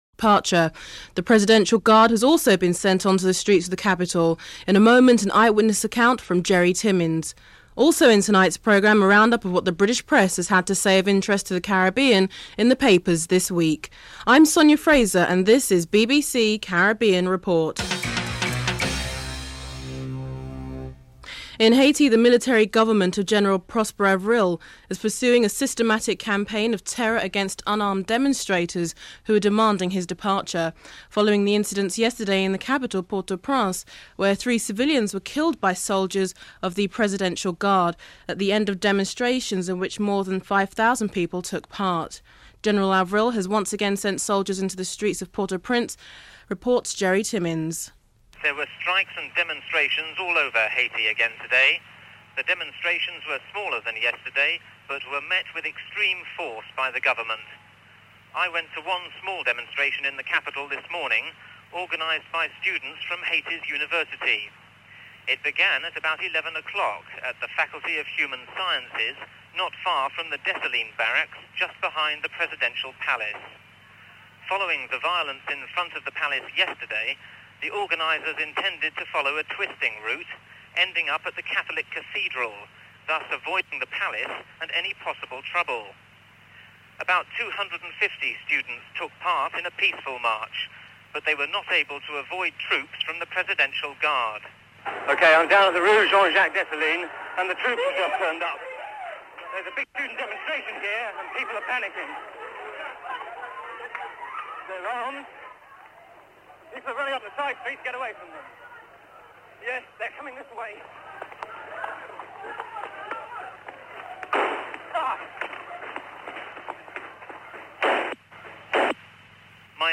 dc.creatorThe British Broadcasting Corporationen_US
dc.descriptionReport begins during the headline news.en_US
dc.description.tableofcontents5. Sporting segment. Christopher Martin-Jenkins reports on the upcoming second test match between England and the West Indies (12:47-14:32)en_US